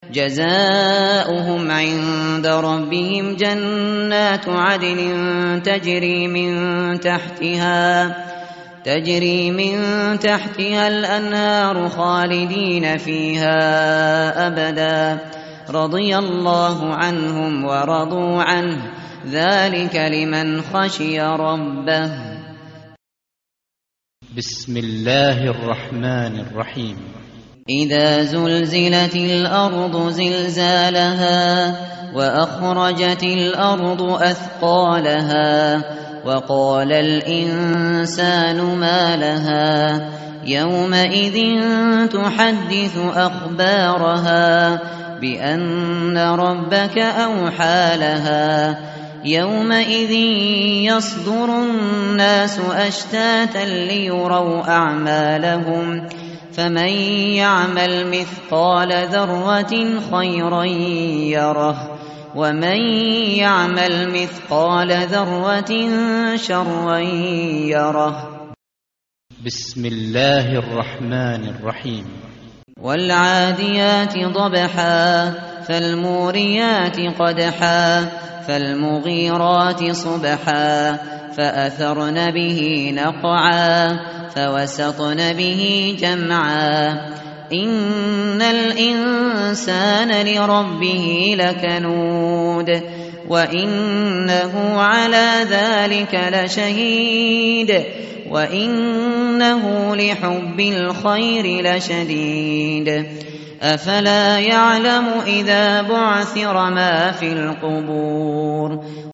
متن قرآن همراه باتلاوت قرآن و ترجمه
tartil_shateri_page_599.mp3